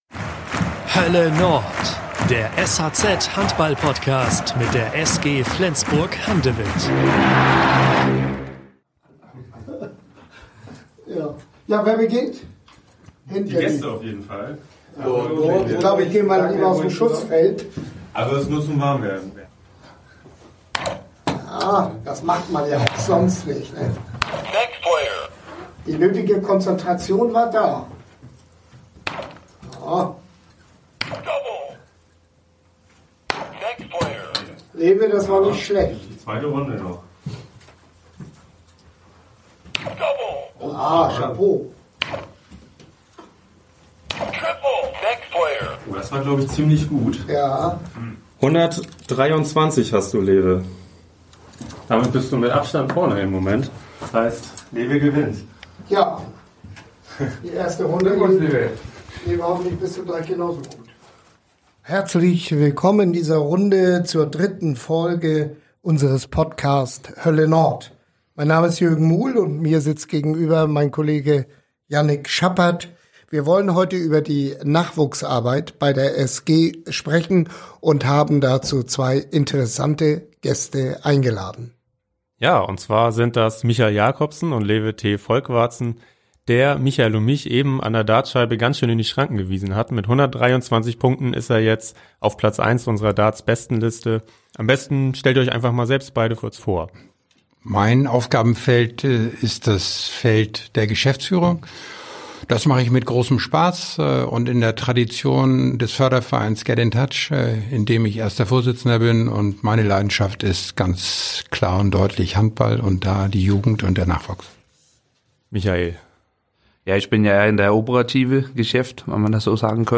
zu Gast im „Hölle Nord“-Podcast-Studio des sh:z